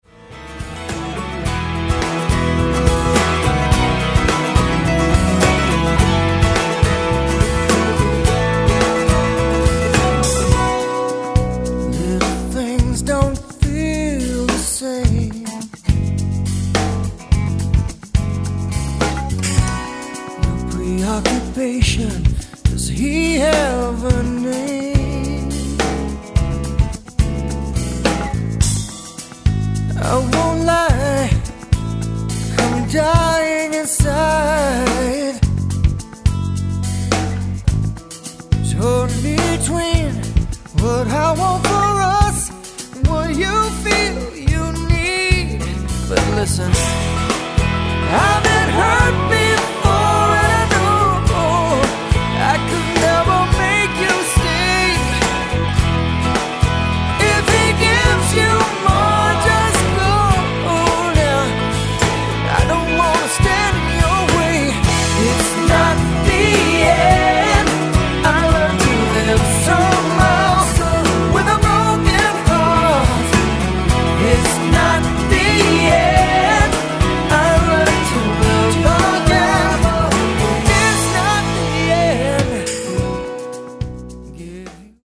vocals, guitars, mandolin
drums, percussion, vocals
piano, organ, guitars, mandolin
bass
saxophones
background vocals
the tempermill, ferndale, michigan